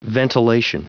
Prononciation du mot ventilation en anglais (fichier audio)
Prononciation du mot : ventilation